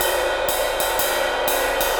Jazz Swing #1 60 BPM.wav